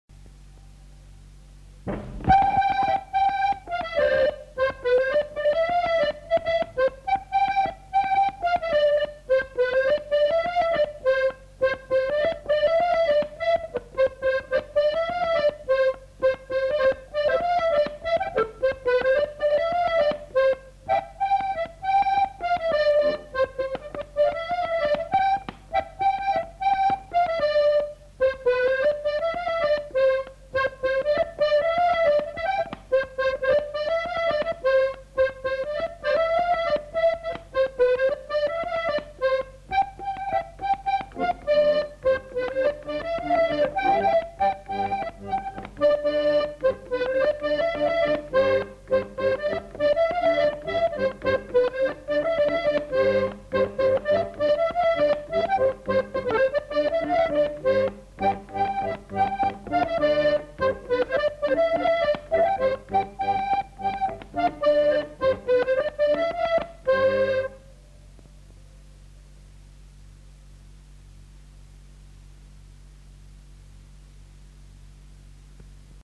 Aire culturelle : Haut-Agenais
Genre : morceau instrumental
Instrument de musique : accordéon chromatique
Danse : rondeau